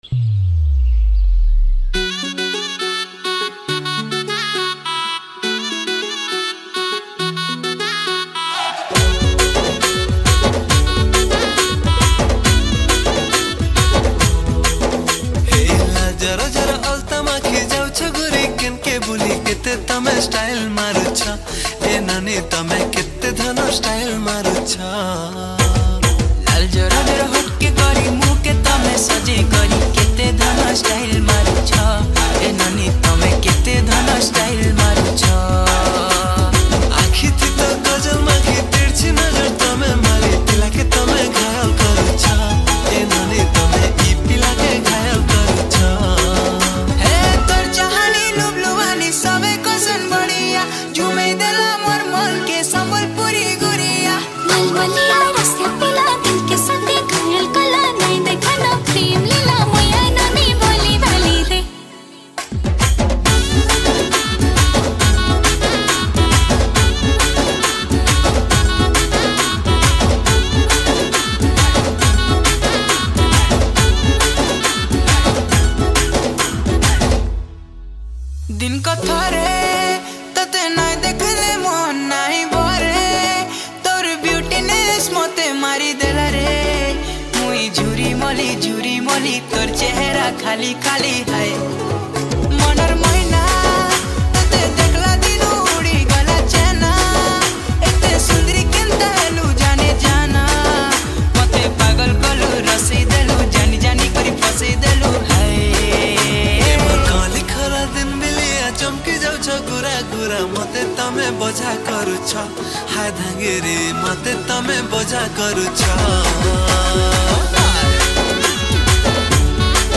Sambalpuri Songs Download